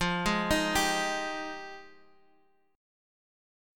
Fm9 chord